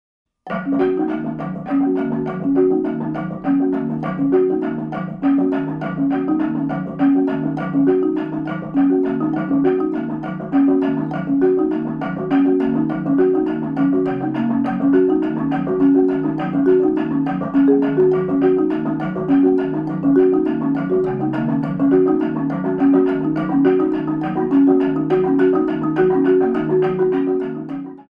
with singing and instrumental music.
marimba, percussion
piano, synthesizer, amadinda
shakhuhatchie, alto flute, ankle bells